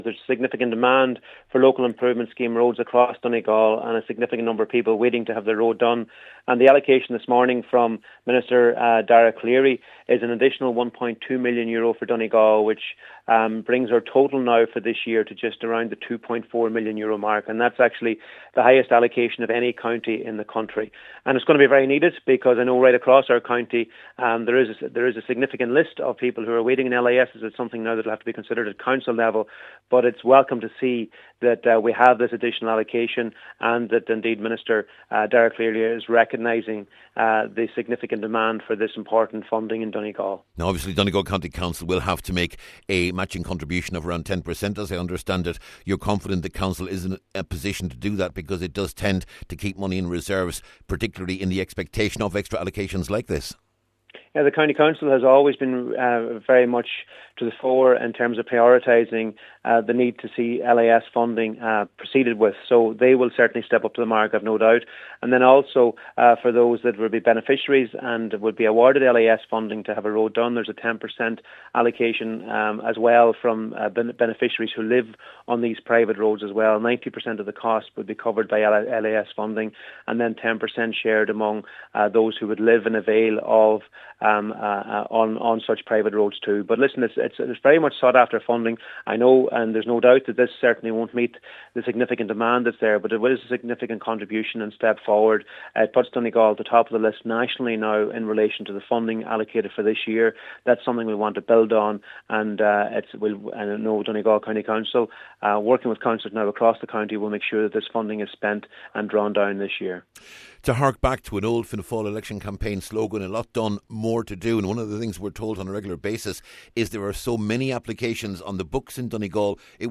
Junior Minister Charlie McConalogue says Donegal has again received the highest LIS allocation in the state this year, and acknowledges much more is needed……….